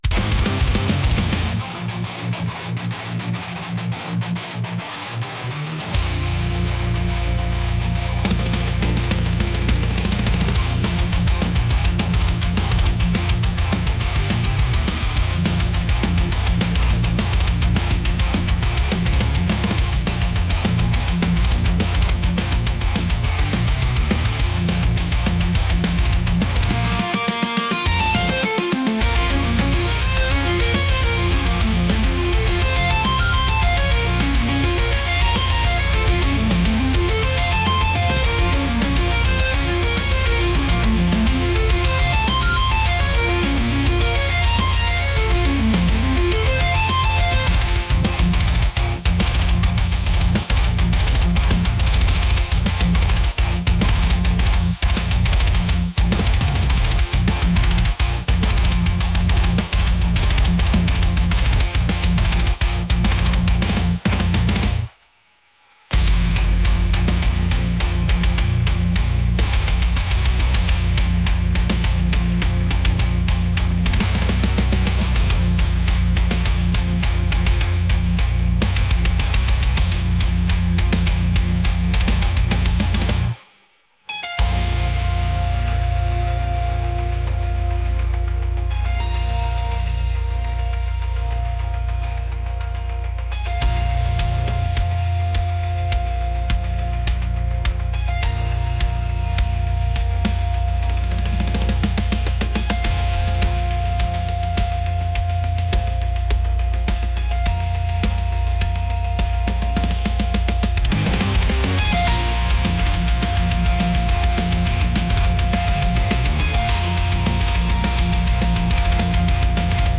metal | aggressive | heavy